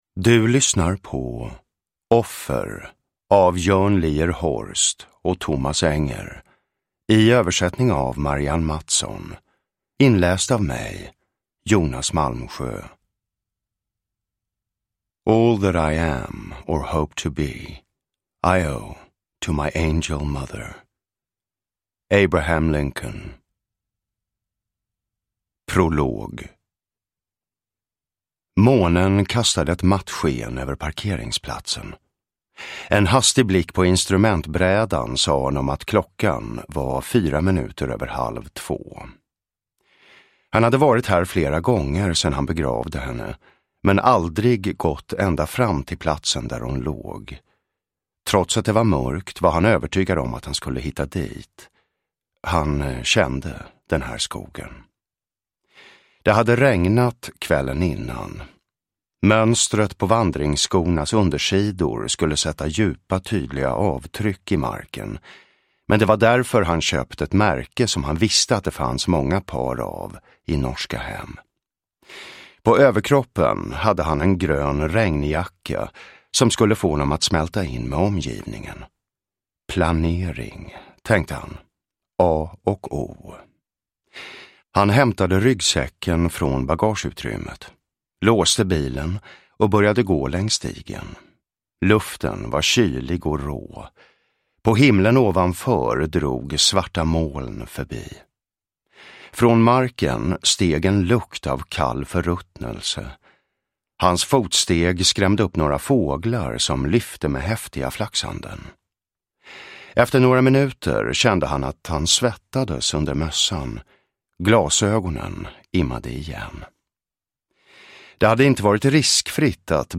Offer (ljudbok) av Thomas Enger